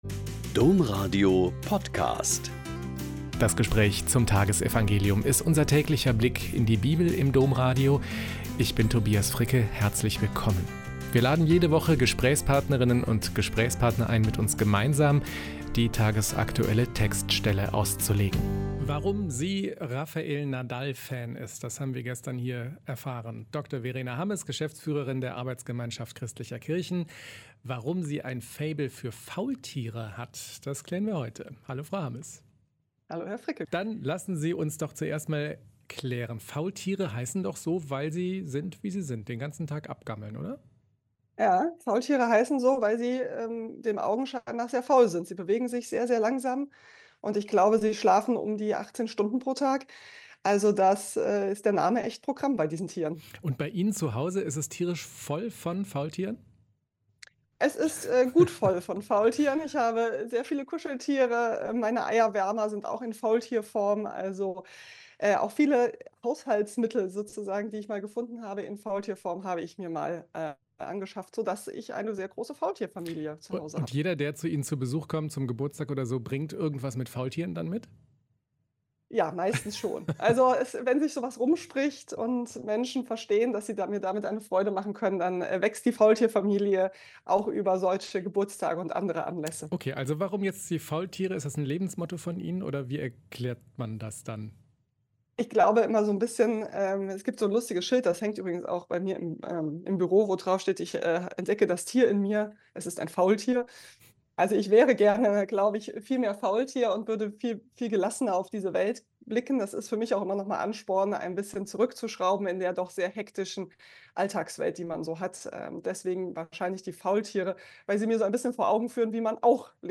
Mt 7,21-29 - Gespräch